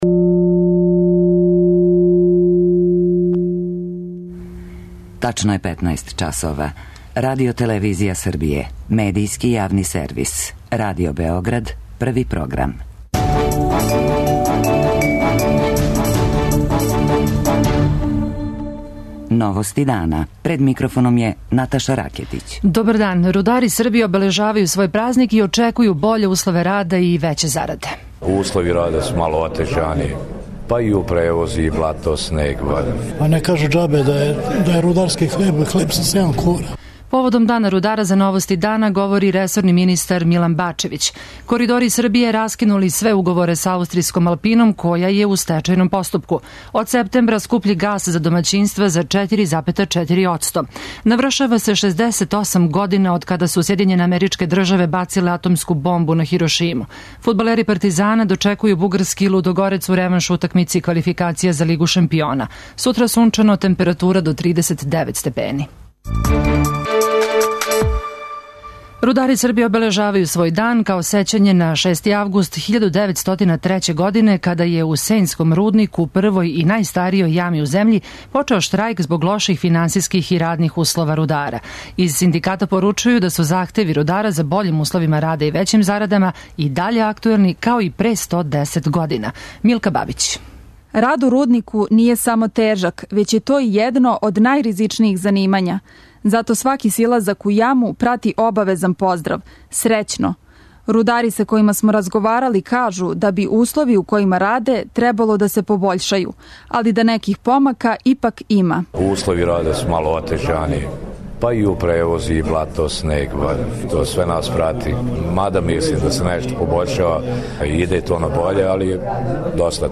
Поводом дана рудара саговорник емсије Новости дана је ресорни министар Милан Бачевић.